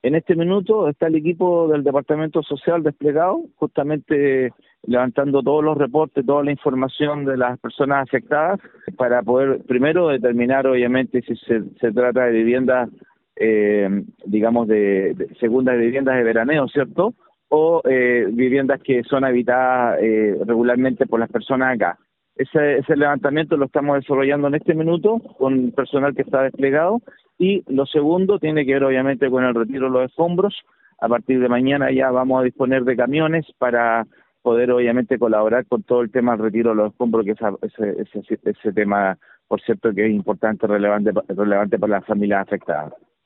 Por último, el Alcalde de San Juan de la Costa señaló que el Departamento Social se encuentra levantando la información necesaria acerca de quienes fueron afectados por el incendio, además de que a contar de mañana martes comenzará la remoción de escombros.